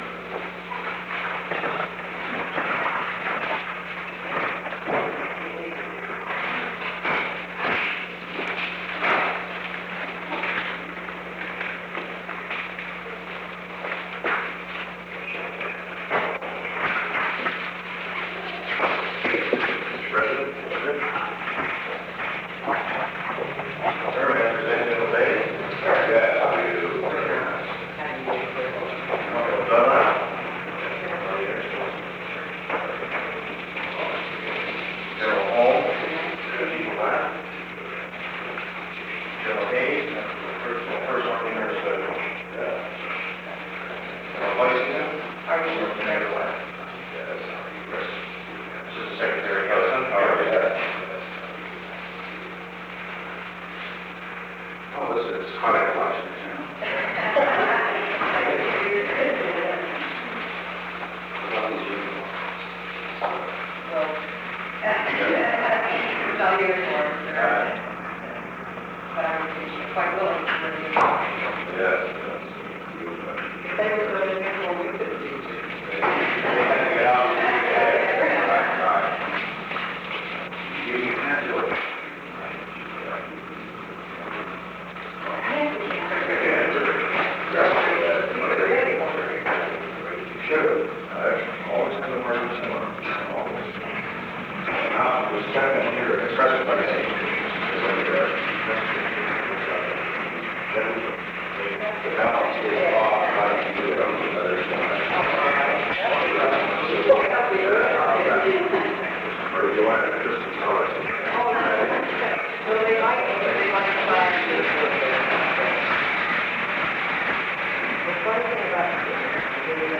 Recording Device: Oval Office
The Oval Office taping system captured this recording, which is known as Conversation 630-012 of the White House Tapes.
[General conversation]